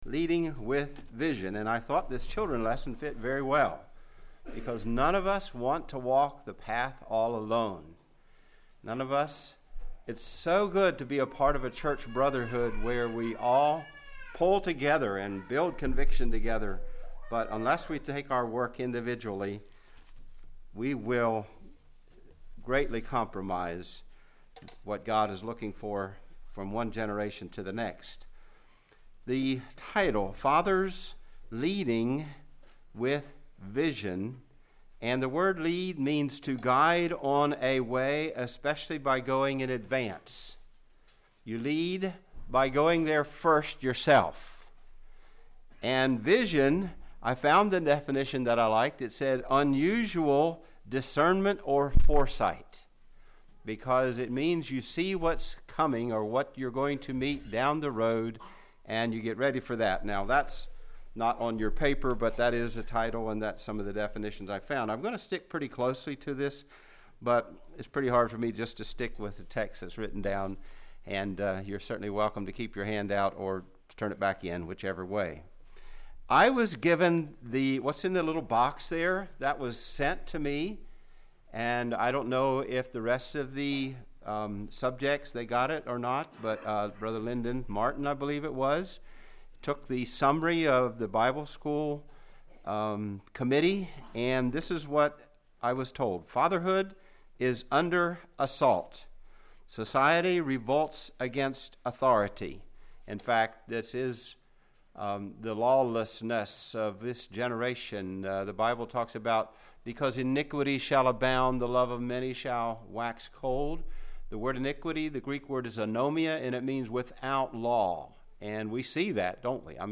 This sermon is about the role of the father in a home. We examined the current trends in society, ageless Biblical principles and practical pointers for today.